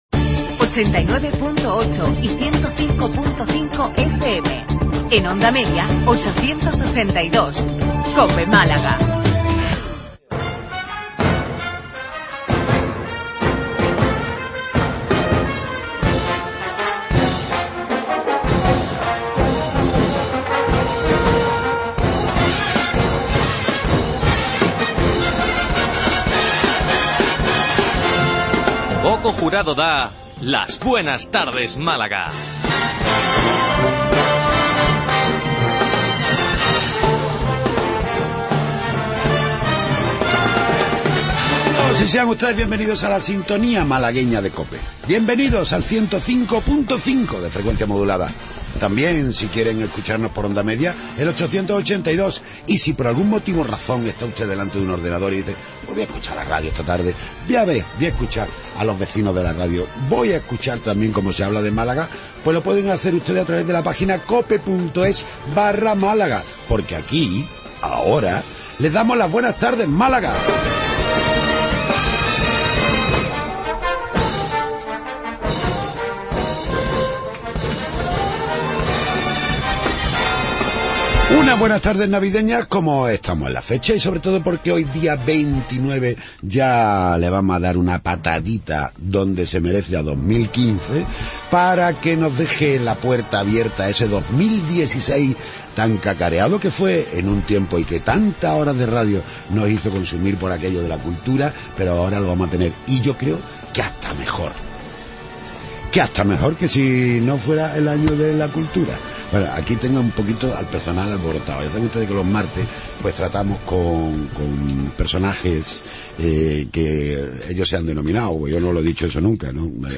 Tertulia de los temas que interesan a Málaga y los Malagueños.